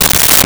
Wood Crack 02
Wood Crack 02.wav